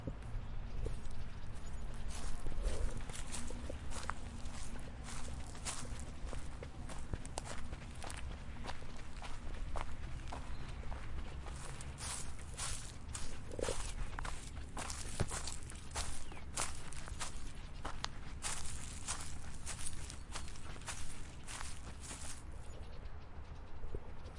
描述：脚步嘎吱嘎吱
声道立体声